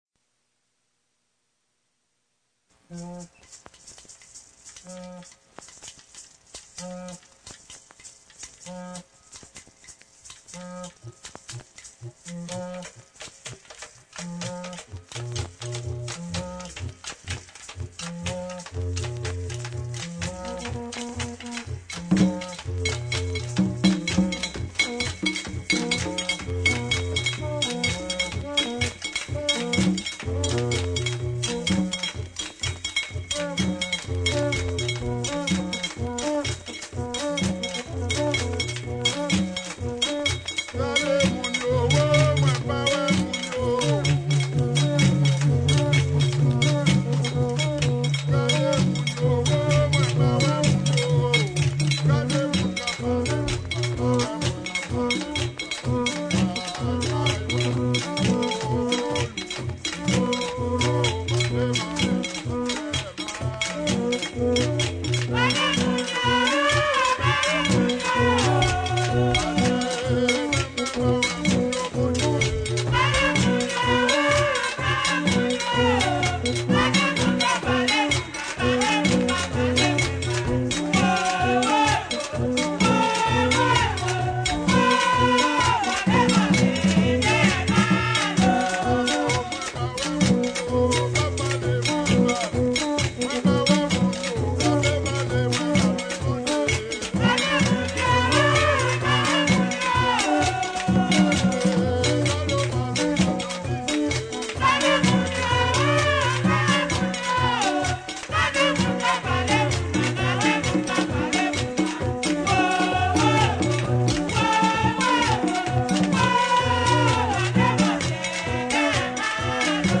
2022 RARA D'HAITI (VACCINE, FLOKLORE HAITIEN) audio closed https